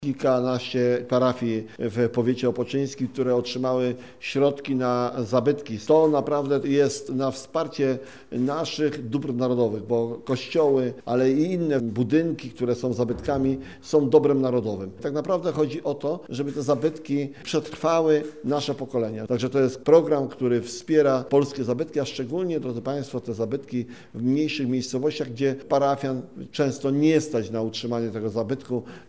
Dzisiaj Informację o dotacjach z Rządowego Programu Odbudowy Zabytków przekazał Minister Rolnictwa i Rozwoju Wsi Robert Telus.